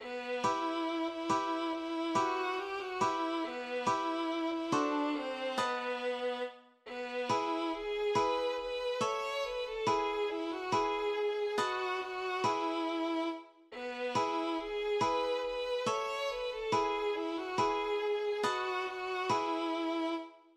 Dichter: (Björn B., Walter Scherf) / Komponist: (traditional)